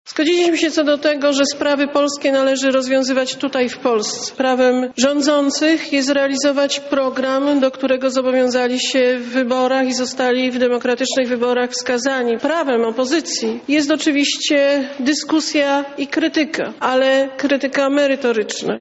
– Najważniejszy jest interes Polski i Polaków- mówiła po spotkaniu z szefami klubów parlamentarnych premier Beata Szydło.